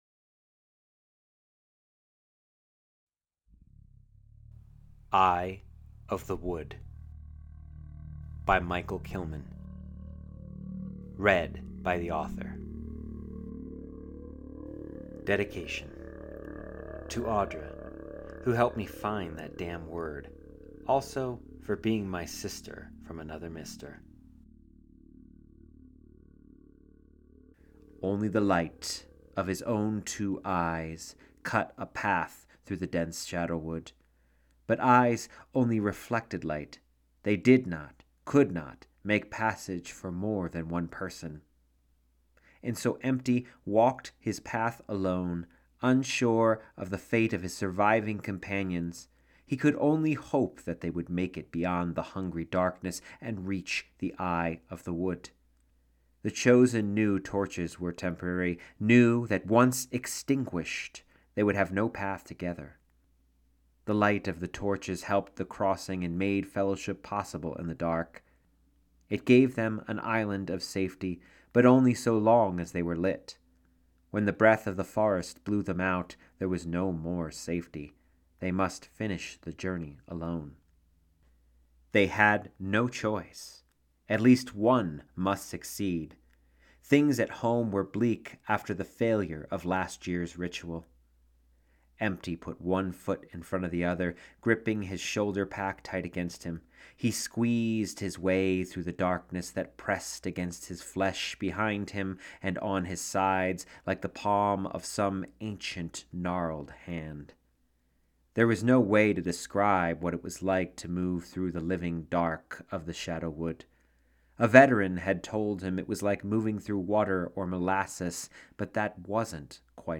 Eye of the Wood Audio Version Download Eye of The Wood PDF Version Download Continue the Journey If Eye of the Wood drew you in , you may want to continue with my dark fantasy horror series, Shades & Shapes in the Dark.
eye-of-the-wood-audio-story.mp3